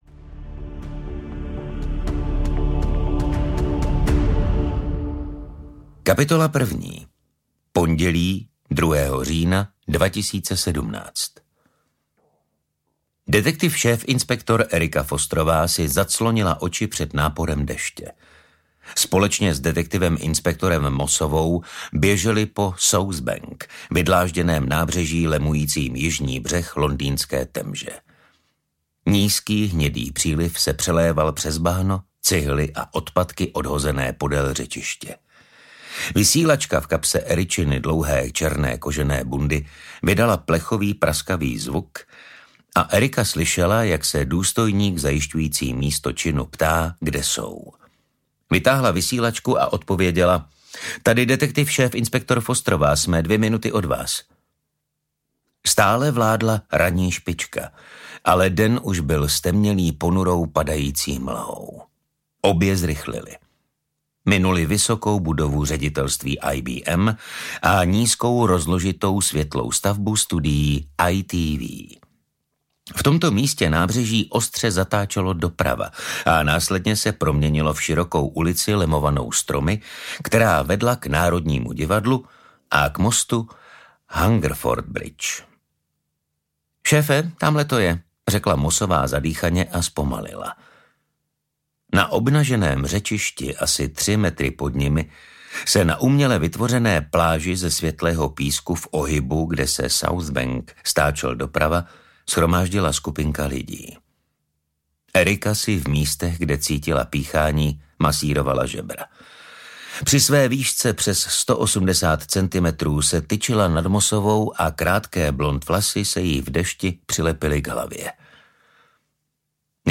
Chladnokrevně audiokniha
Ukázka z knihy